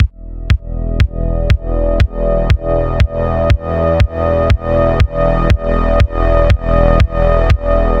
The kick signal is muted at the moment since it’s being sent to the compressor’s sidechain input.
The final loop:
loop_compression.mp3